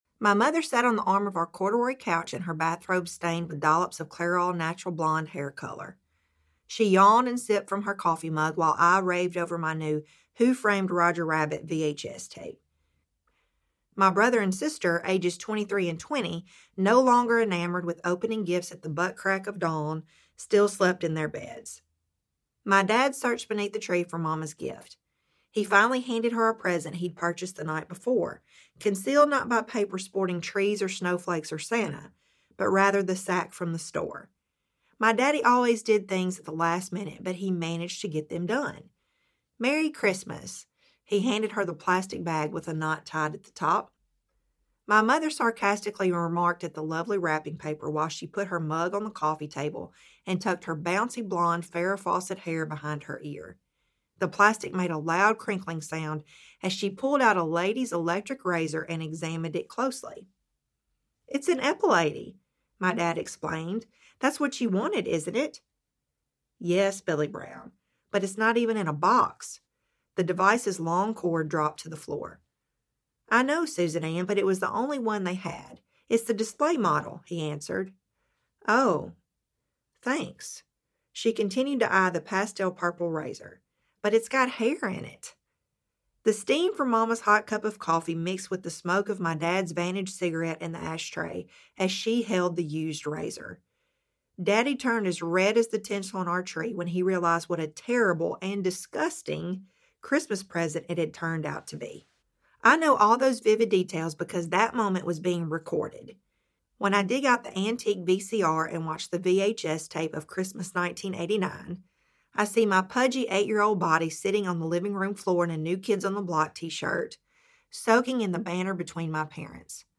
Can’t Make This Stuff Up! Audiobook
Narrator
4.2 Hrs. – Unabridged